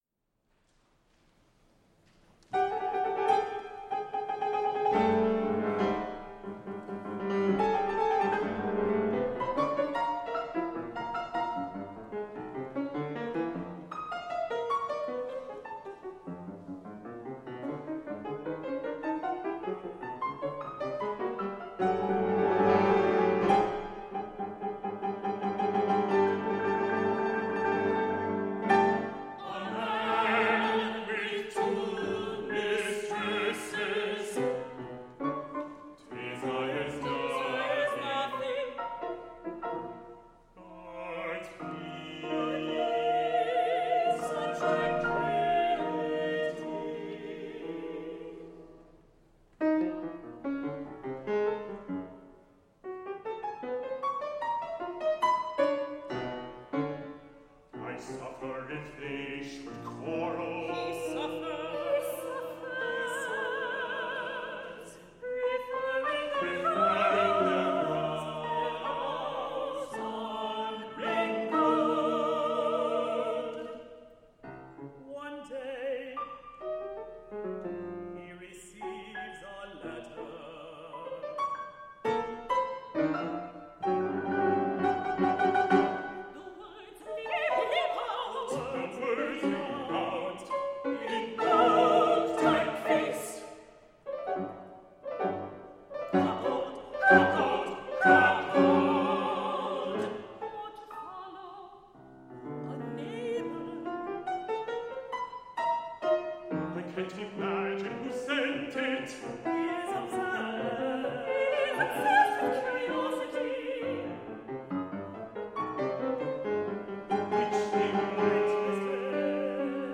Vocal quartet and piano 4-hands Duration